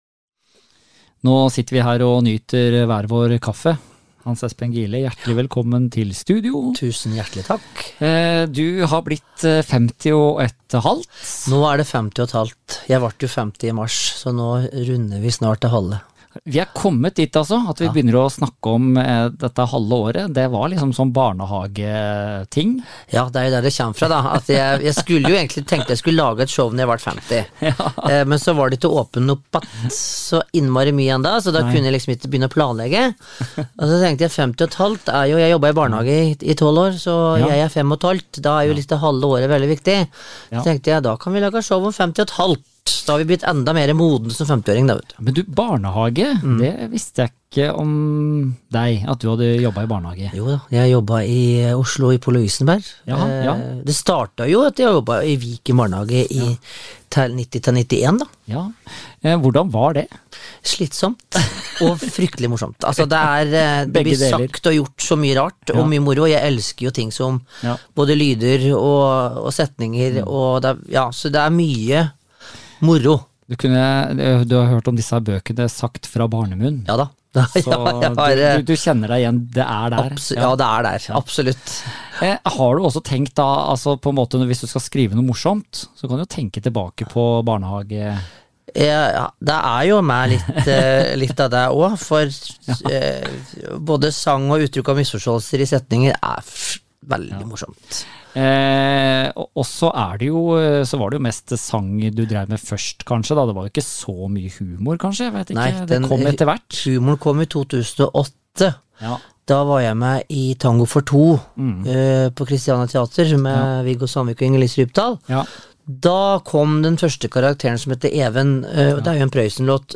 Vi har tatt en hyggelig prat over en kaffe i vårt studio.